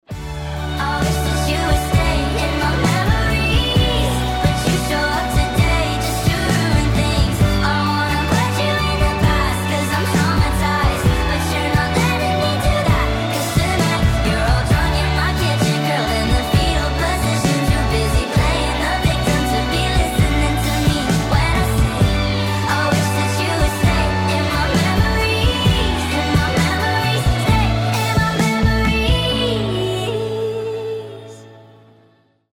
• Качество: 320, Stereo
мужской голос
ремиксы
Как и предыдущий, только шустрее